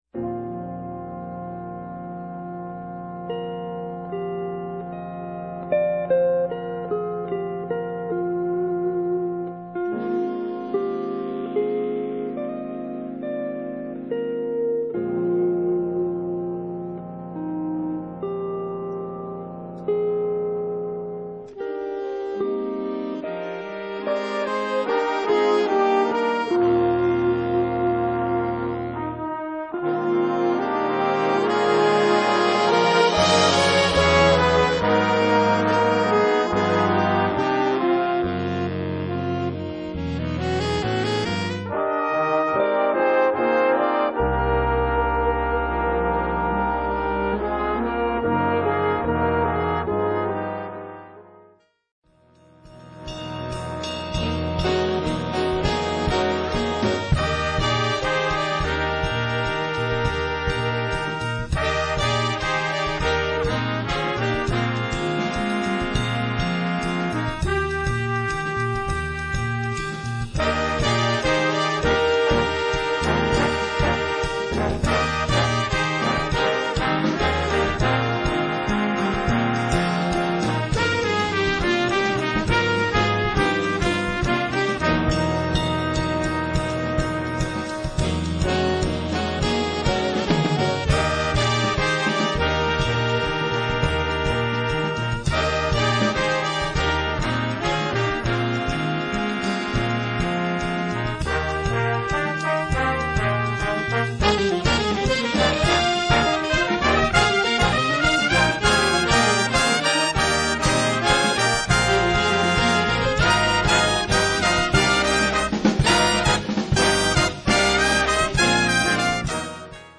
Big band jazz
LA based big band of top studio pros